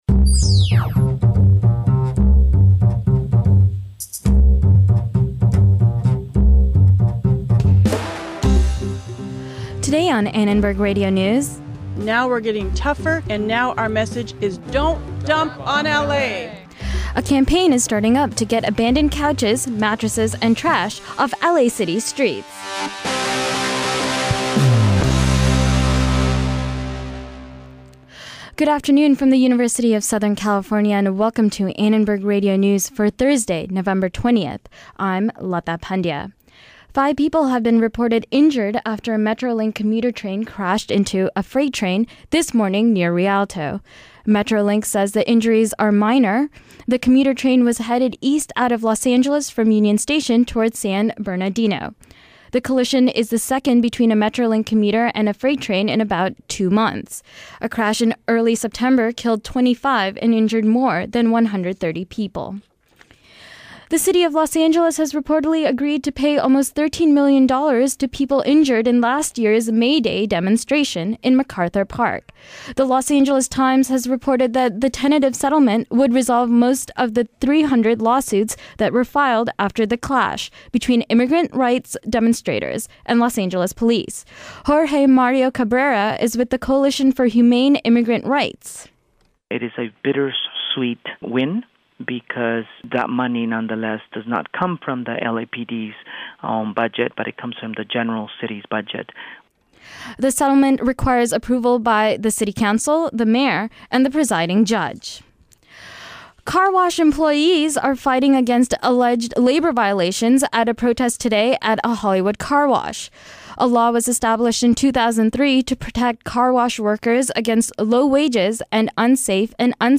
ARN Live Show - November 20, 2008 | USC Annenberg Radio News